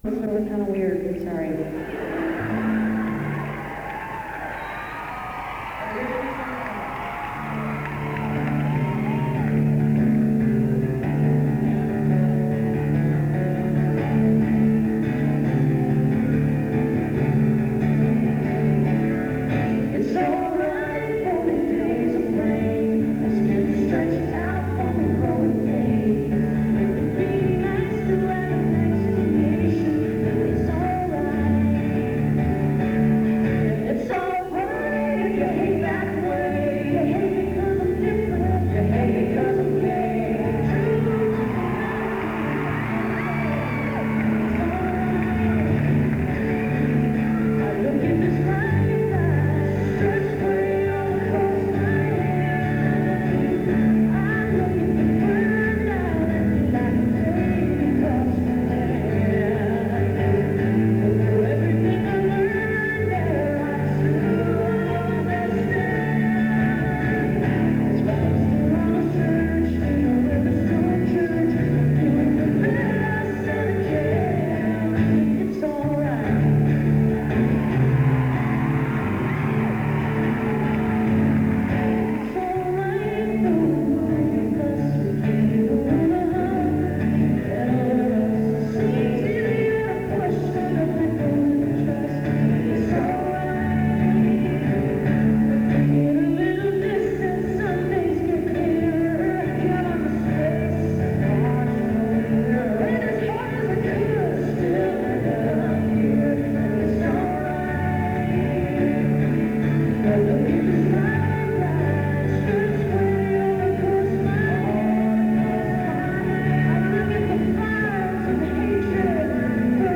northrup auditorium - minneapolis, minnesota